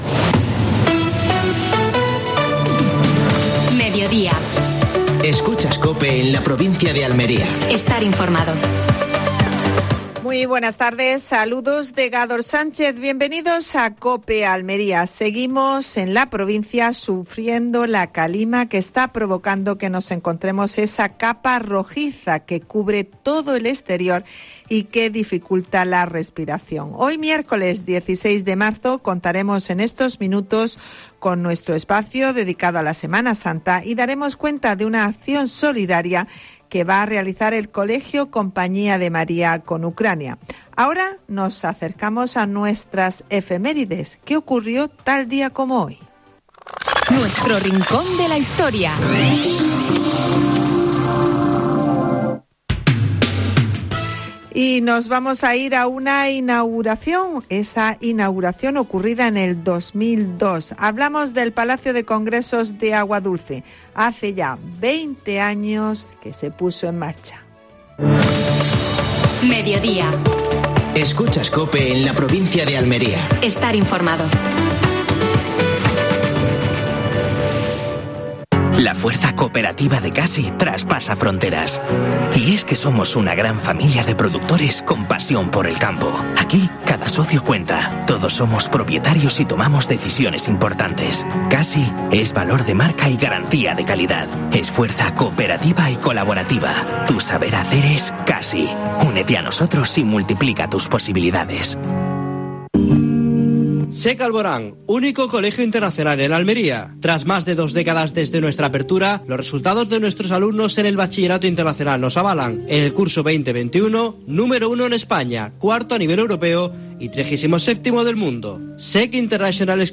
Actualidad en Almería. Entrevista a la Compañía de María.